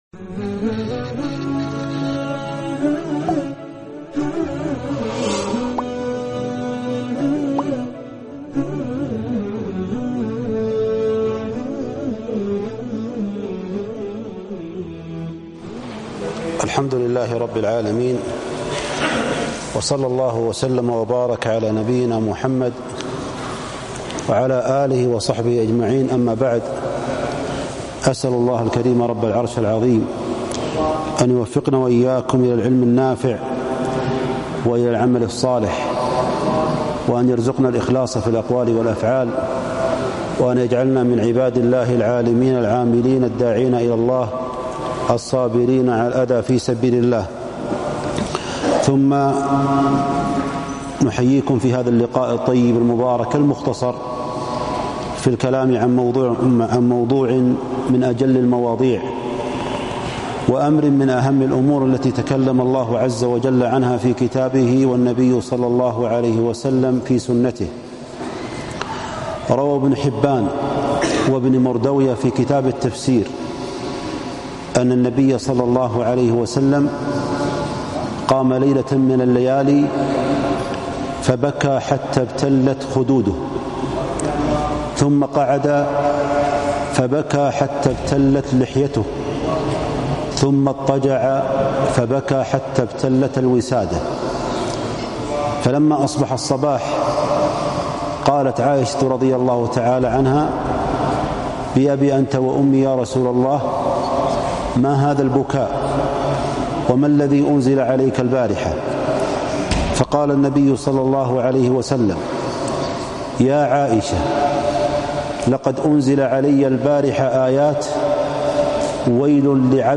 فضل ذكر الله - كلمة